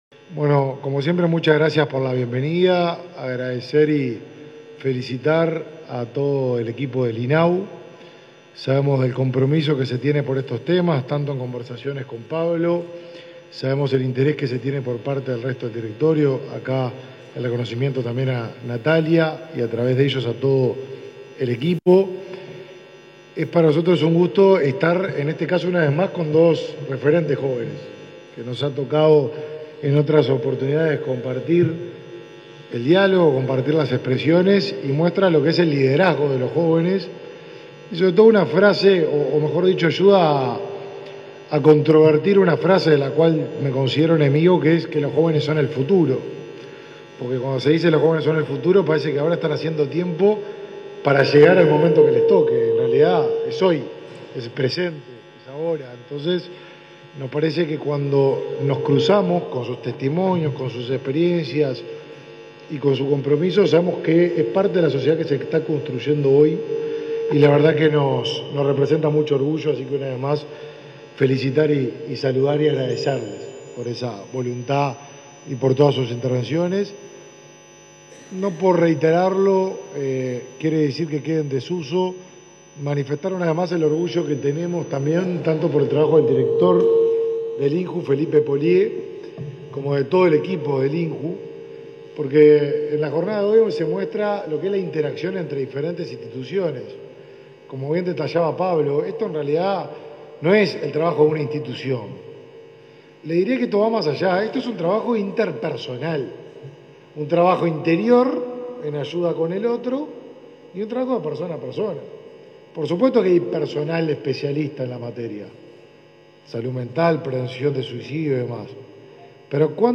Conferencia de prensa por el entrega de kit del INAU para la campaña de salud mental del Mides
Conferencia de prensa por el entrega de kit del INAU para la campaña de salud mental del Mides 15/11/2022 Compartir Facebook X Copiar enlace WhatsApp LinkedIn En el marco de la campaña sobre salud mental y prevención del suicidio Ni Silencio Ni Tabú, impulsada por el Ministerio de Desarrollo Social, este 15 de noviembre el Instituto del Niño y Adolescente del Uruguay (INAU) se sumó a la iniciativa con la entrega de kits informativos para hogares de todo el país. Participaron del evento el ministro del Mides, Martín Lema, y el presidente del Instituto Nacional de la Juventud (INJU), Felipe Paullier.